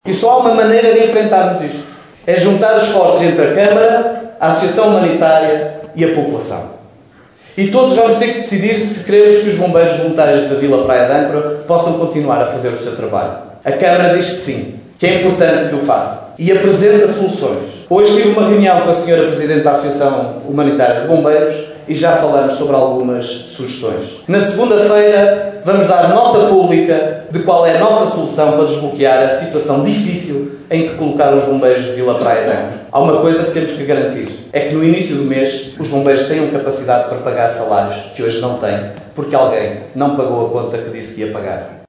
O presidente da Câmara prometeu durante a Assembleia Municipal apresentar esta Segunda-feira soluções para desbloquear as contas dos bombeiros de Vila Praia de Âncora.
assembleia-municipal-bombeiros-vpa-miguel-alves-2.mp3